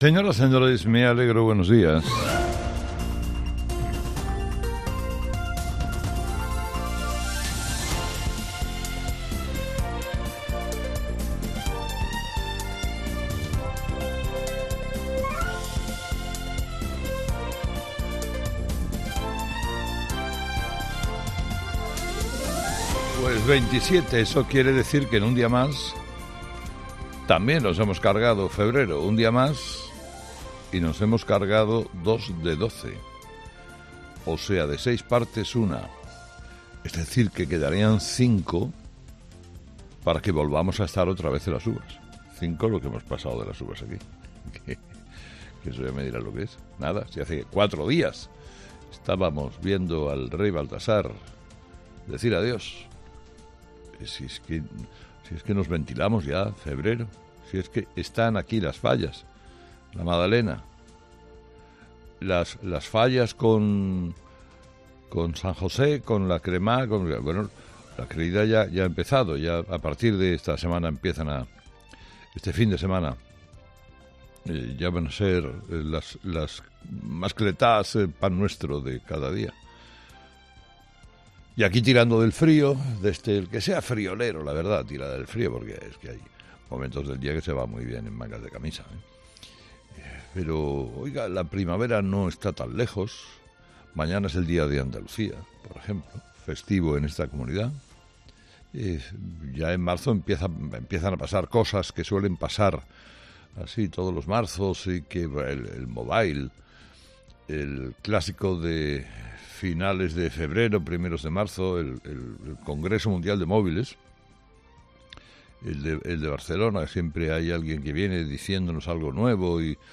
Carlos Herrera repasa los principales titulares que marcarán la actualidad de este lunes 27 de febrero en nuestro país
Carlos Herrera, director y presentador de 'Herrera en COPE', comienza el programa de este lunes analizando las principales claves de la jornada, que pasan, entre otros asuntos, por la moción de censura que registrará VOX contra Pedro Sánchez, en la que Ramón Tamames será su candidato.